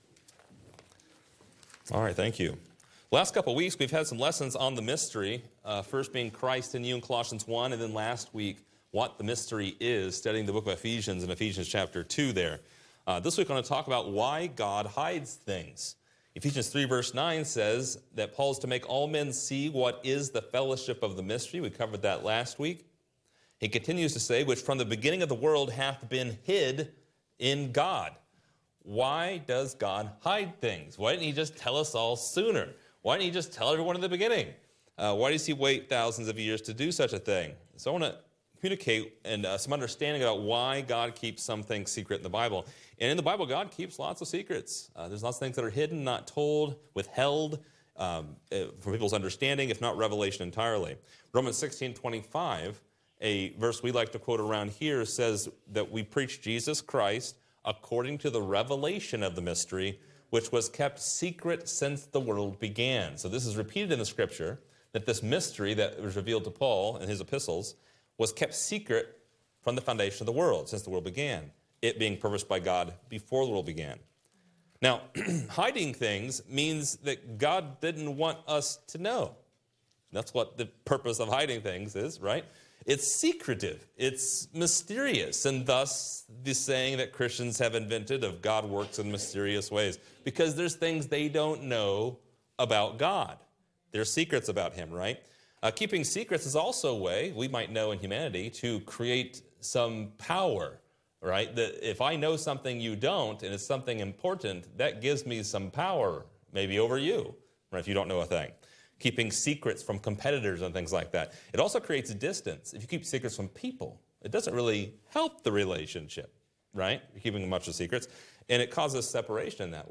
This lesson presents ten reasons why God hides things from humanity in the scriptures. Learn more in this lesson!